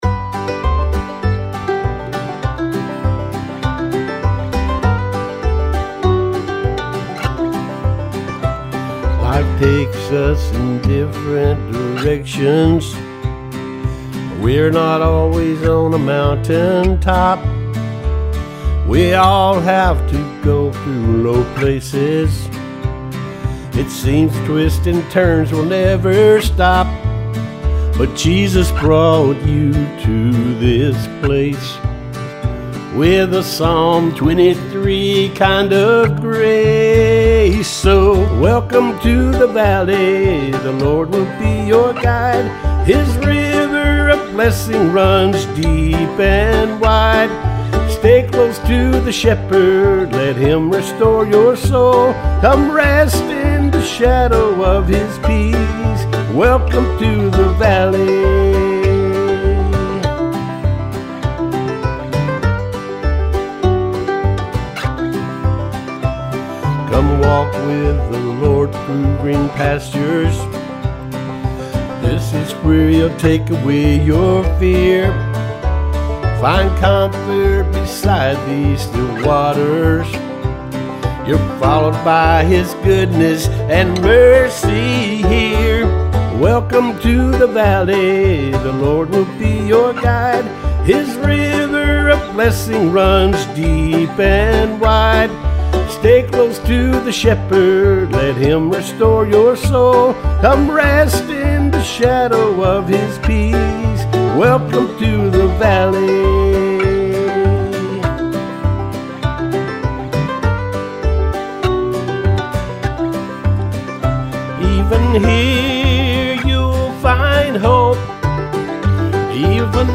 Bluegrass Demo